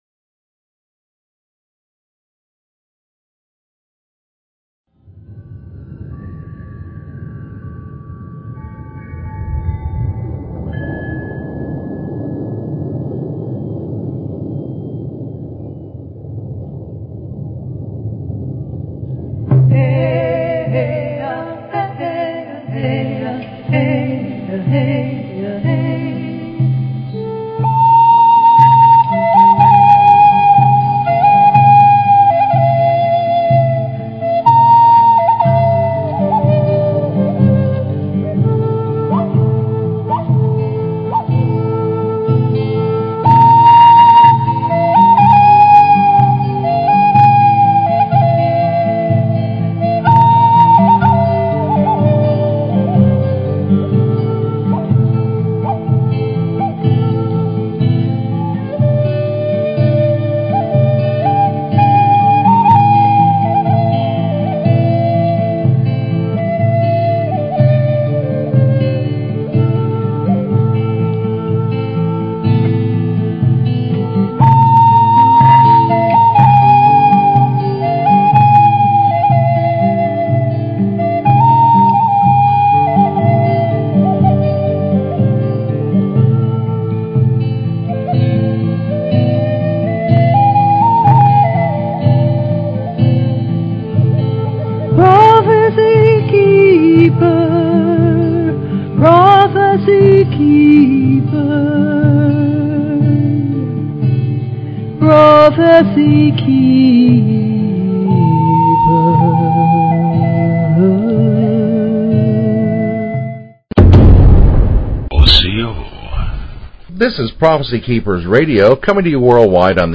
Talk Show Episode, Audio Podcast, Prophecykeepers_Radio and Courtesy of BBS Radio on , show guests , about , categorized as